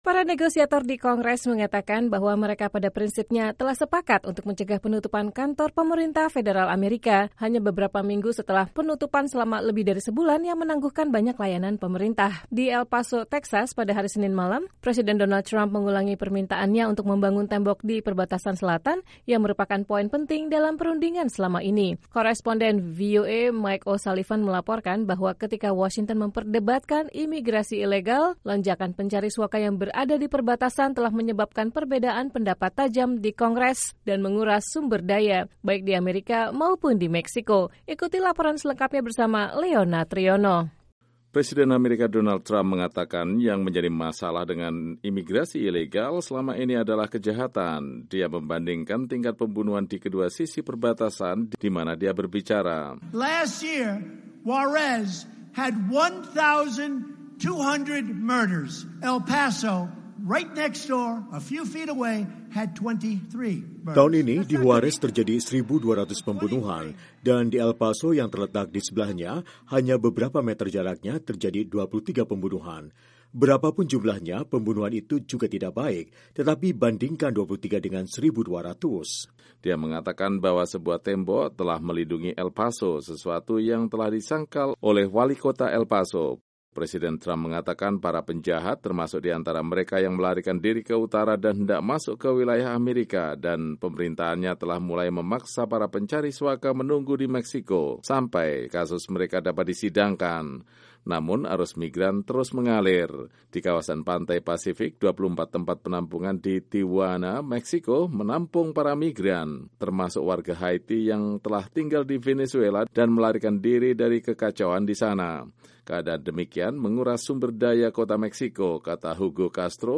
Presiden Donald Trump dalam rally di El Paso, Texas, 11 Februari 2019.